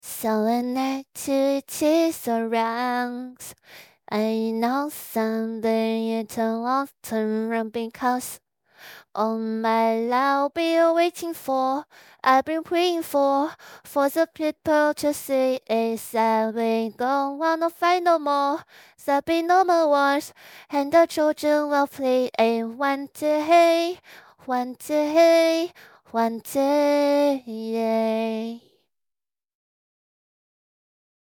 Ai 向晚 少女音 RVC模型
唱歌表现